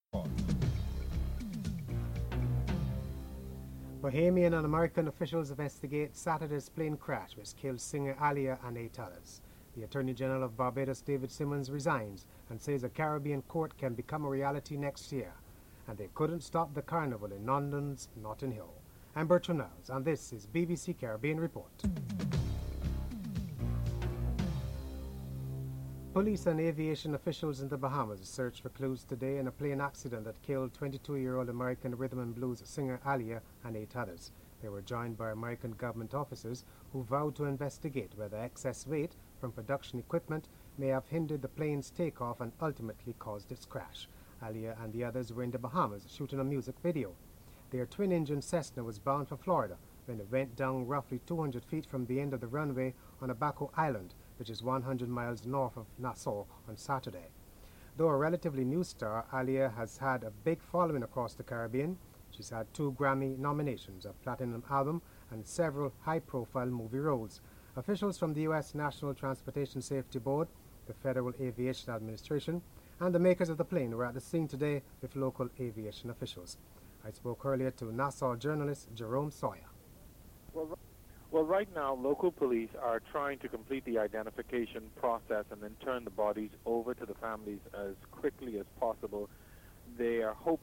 1. Headlines (00:00-00:26)
Attorney General David Simmons is interviewed